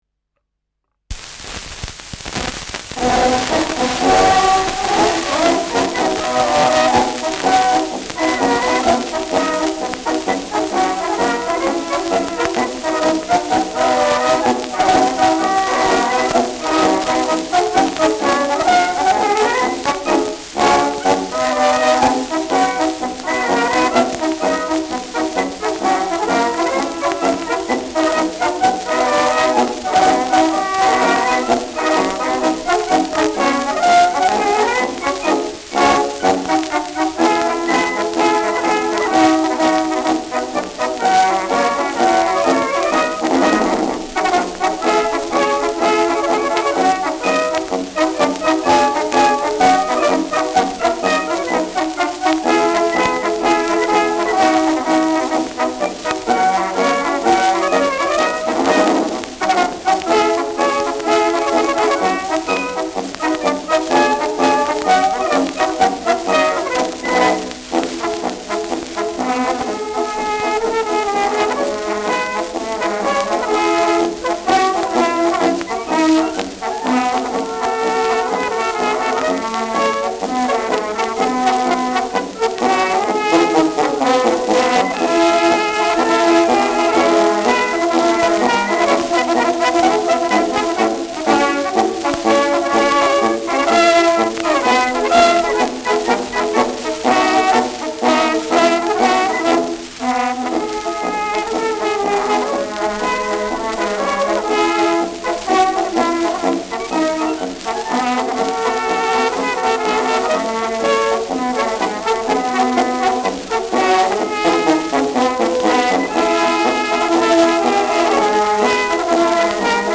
Schellackplatte
[Wien] (Aufnahmeort)